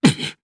DarkKasel-Vox_Damage_jp_01.wav